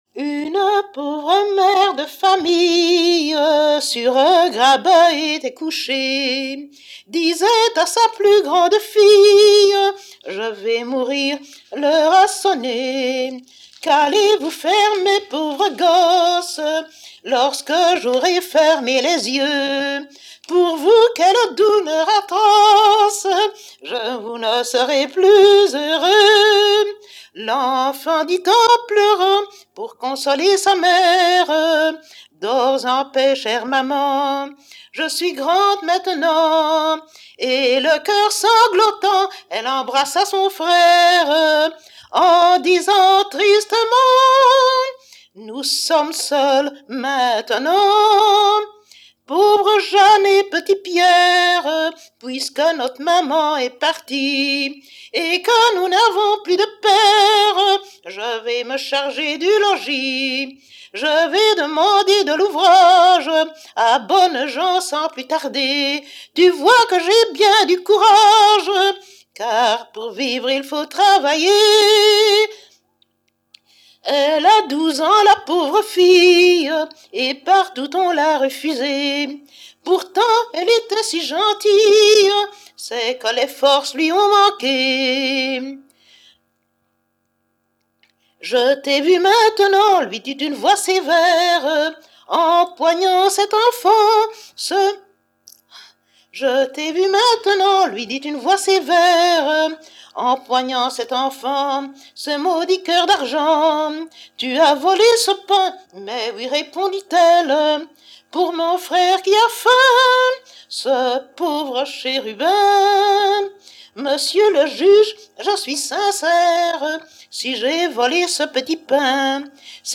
Genre : chant
Type : chanson narrative ou de divertissement
Lieu d'enregistrement : Schaerbeek
Support : bande magnétique